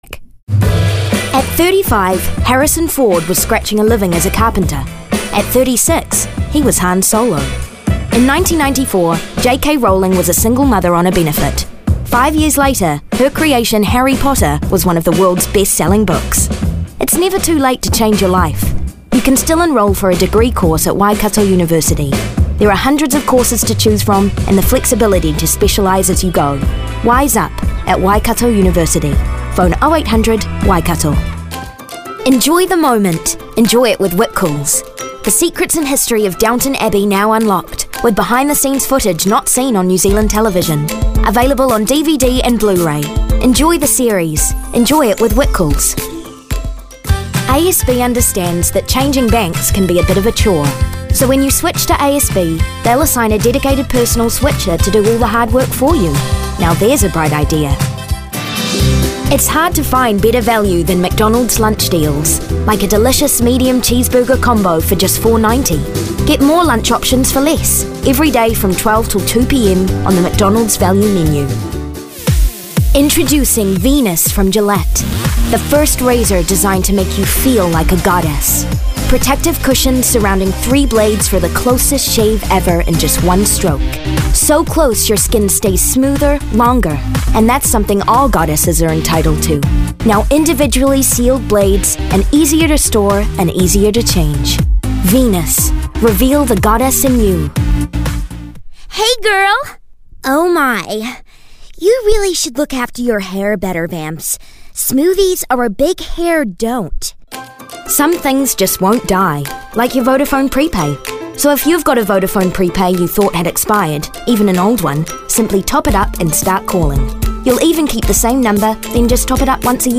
Demo
Adult, Young Adult
Has Own Studio
English | New Zealand
animation
commercial
corporate narration
character - child
character - teenager
comedic
friendly
quirky